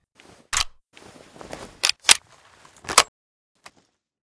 thompson_reload_stick.ogg